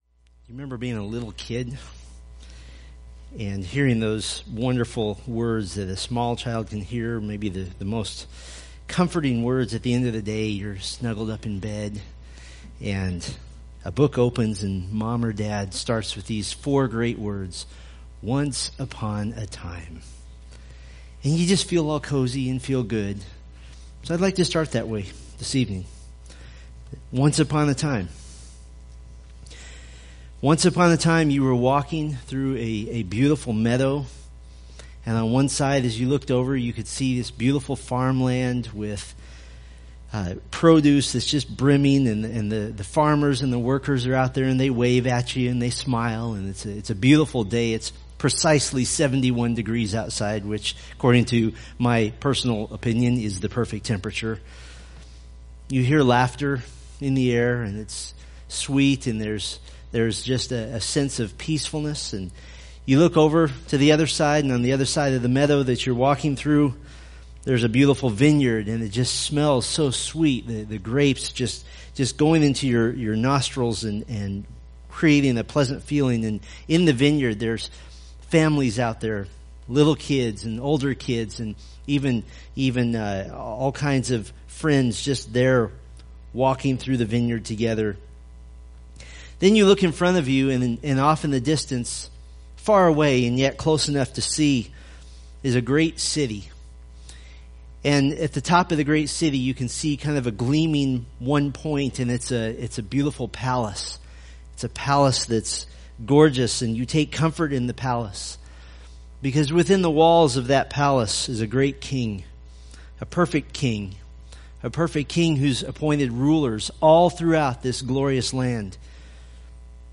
Preached August 28, 2016 from Isaiah 32